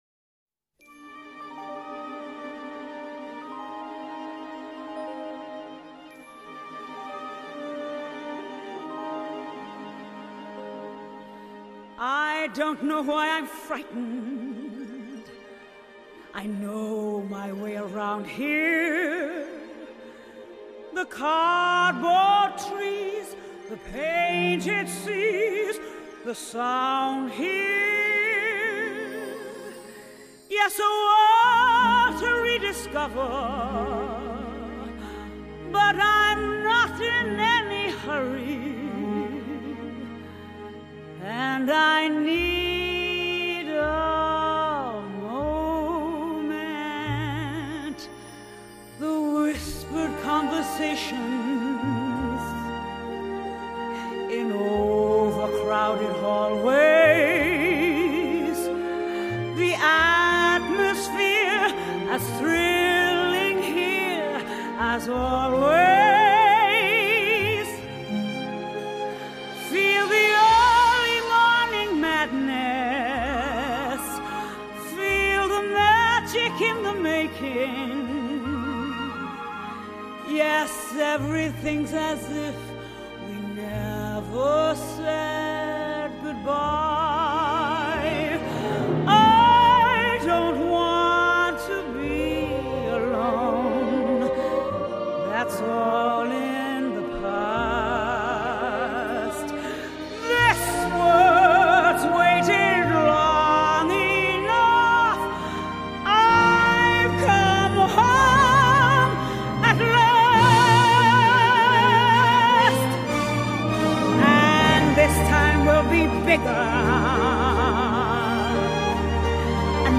Genre: Jazz